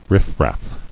[riff·raff]